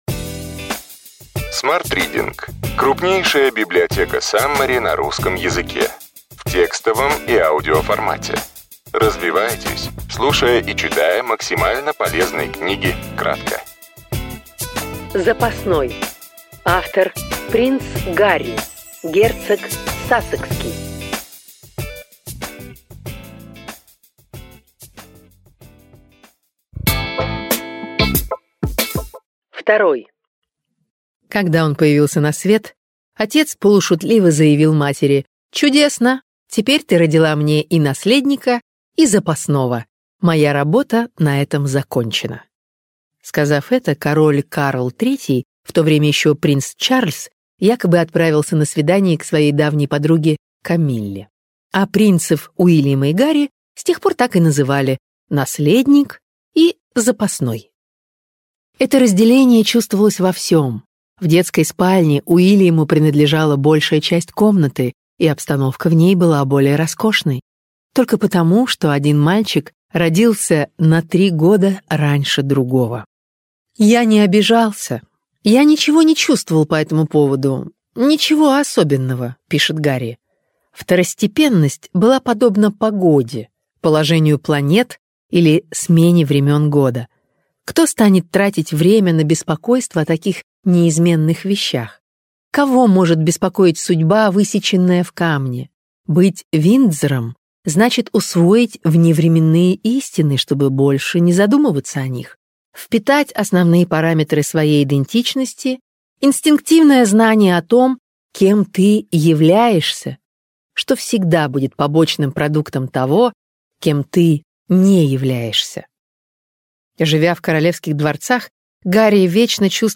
Аудиокнига Запасной. Принц Гарри, герцог Сассекский. Саммари | Библиотека аудиокниг